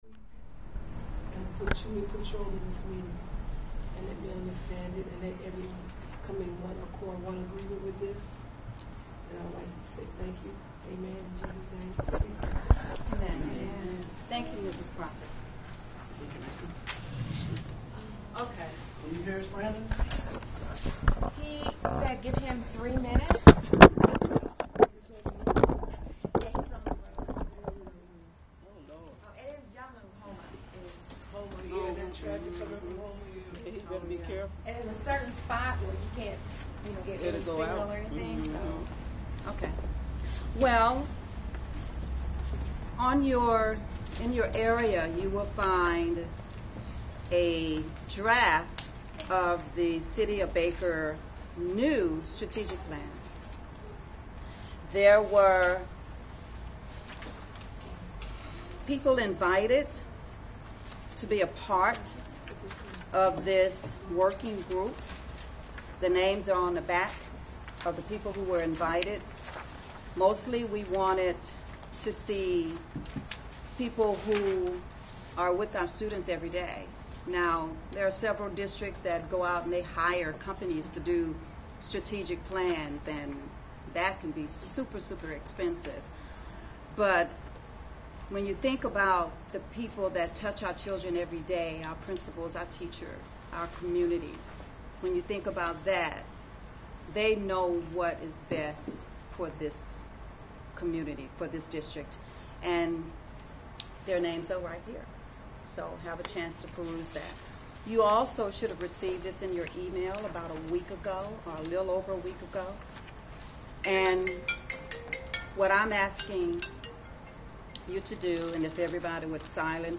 Board Meeting Audio
March 2024 Board Meeting-Work Session.m4a